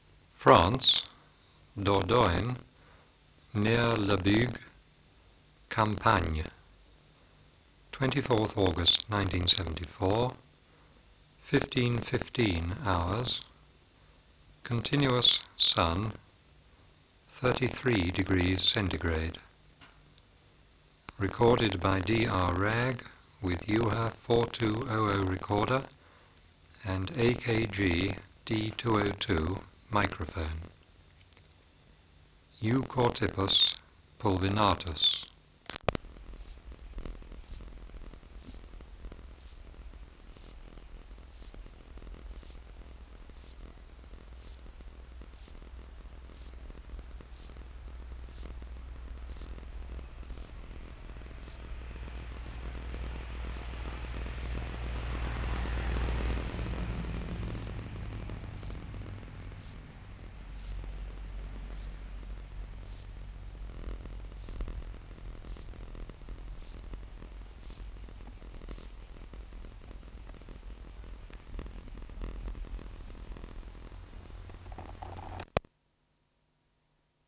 Recording Location: Europe: France: Dordogne, near Le Bugue, Campagne
Air Movement: Light breeze
Substrate/Cage: On grass
Microphone & Power Supply: AKG D202 (-20dB at 50Hz) Distance from Subject (cm): 10
Recorder: Uher 4200